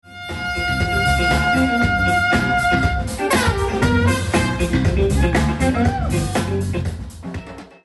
ちょうど最近「ライブをMDで録音したのだけど音が悪いのでどうにかなりませんかね」っていうTRの依頼を受けて勉強がてらいじってたんです（忙しくてMDもらってから１年ぐらい放置してたんだけどね、アヒャヒャ）。
・原音